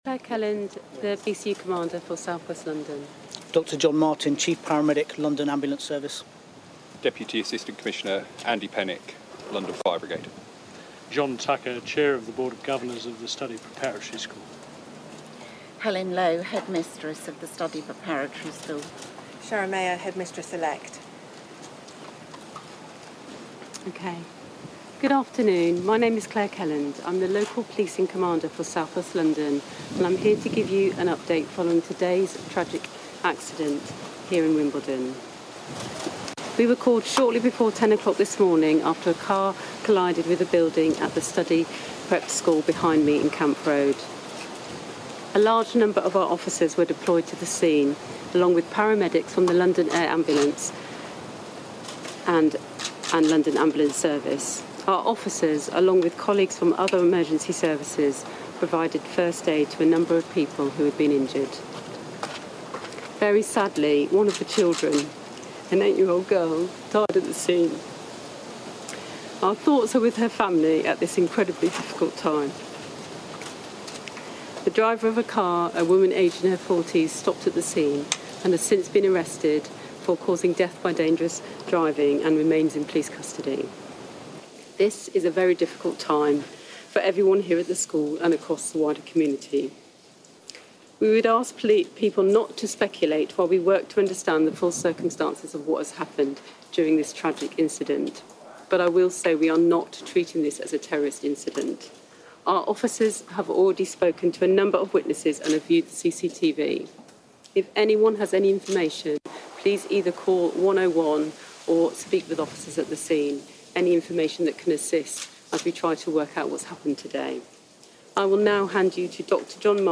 Wimbledon News Conference.